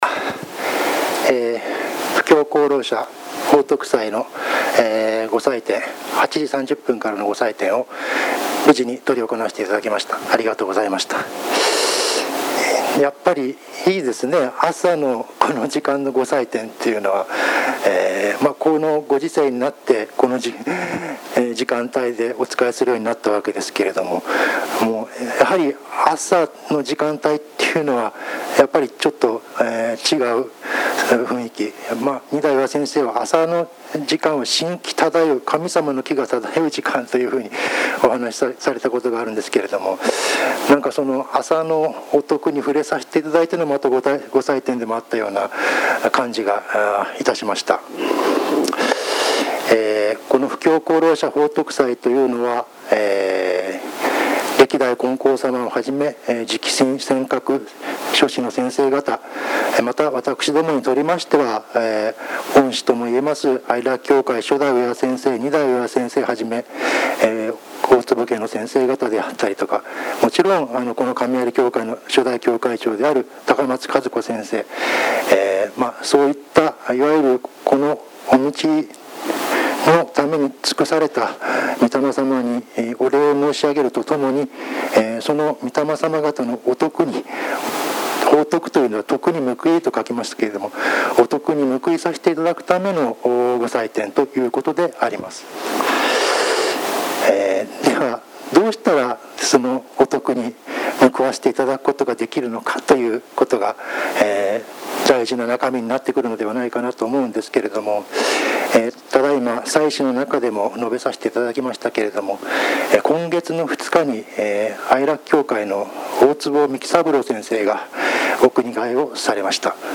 布教功労者報徳祭教話